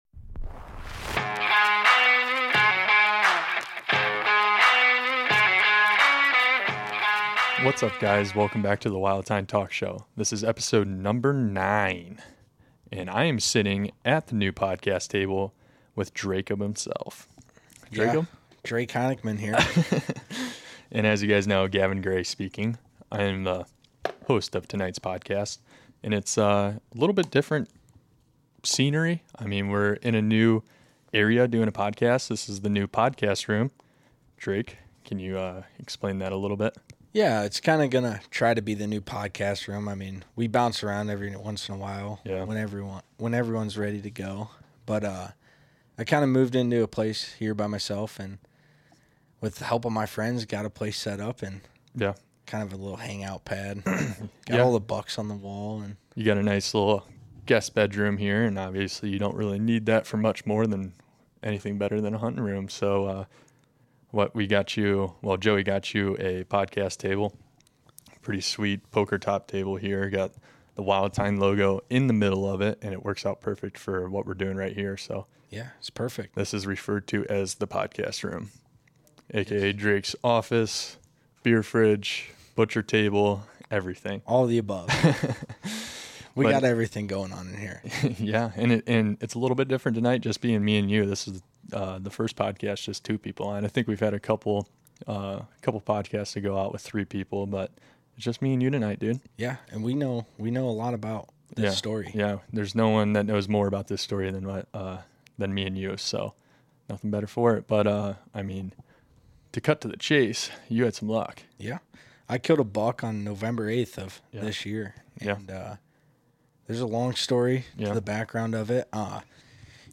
You can expect to listen to the boys just hanging out with each other, as well as some guest appearances from other names in the outdoor industry.